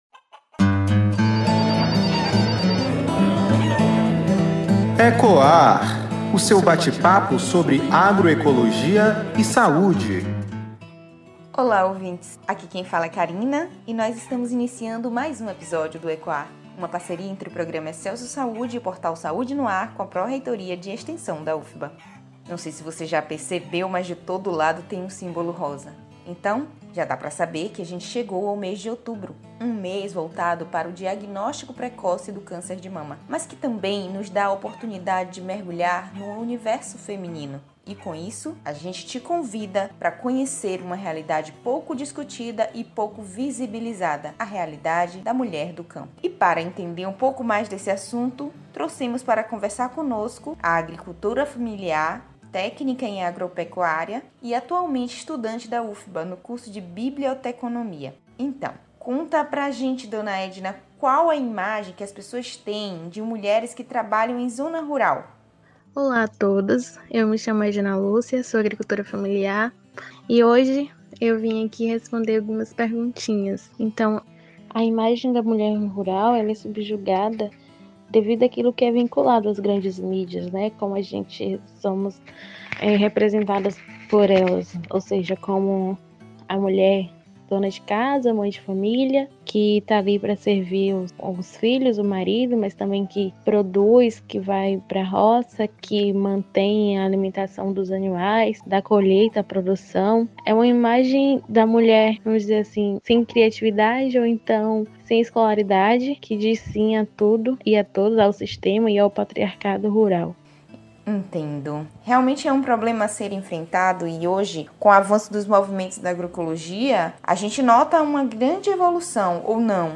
Esse é o tema do Ecoar  Nosso Bate Papo sobre Agroecologia e Saúde.